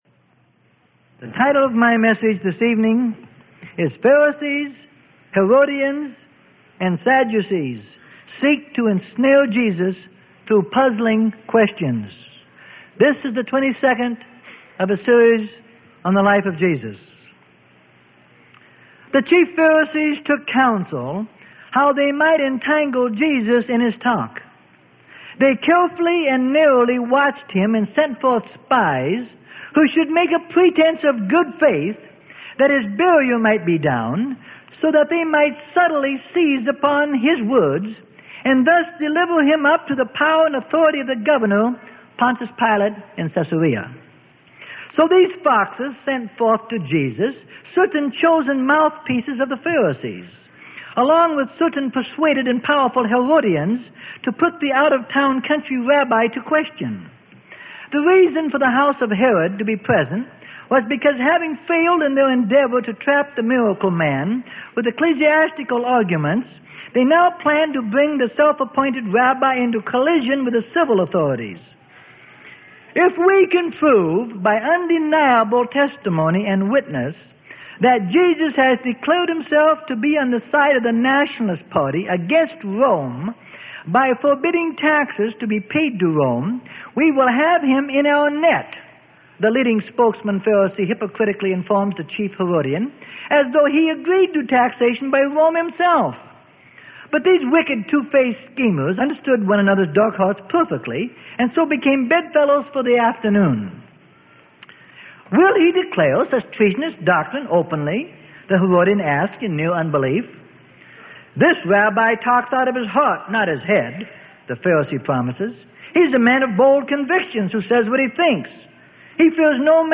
Sermon: The Life Of Jesus - Part 22 Of 33.